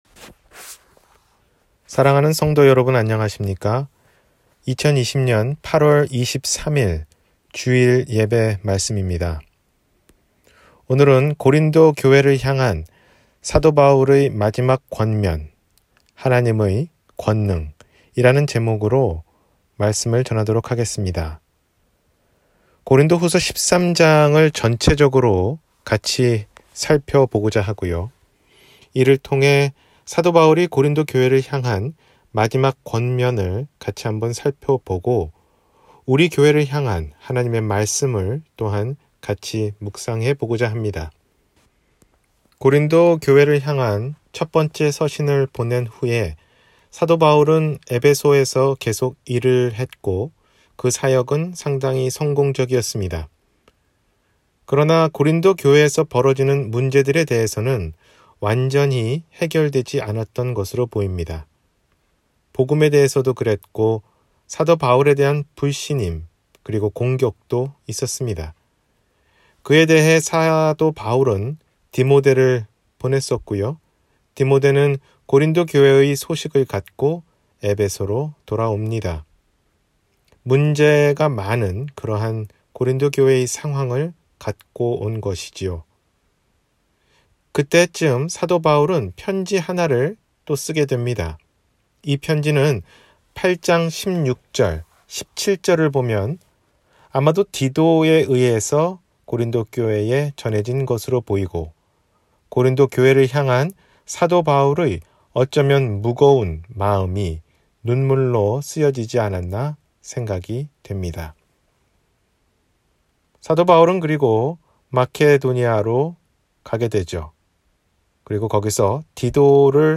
고린도교회를 향한 사도바울의 마지막 권면: 하나님의 권능 – 주일설교